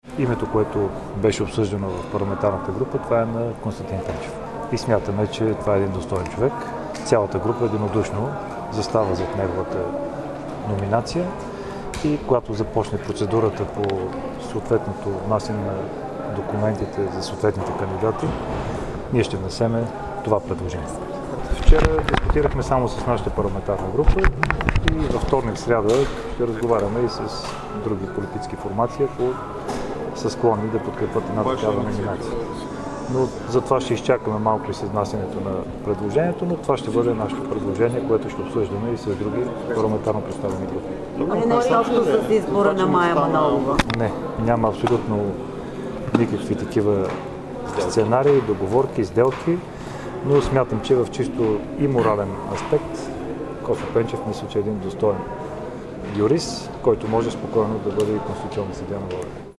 Чуйте повече по темата от Цветан Цветанов: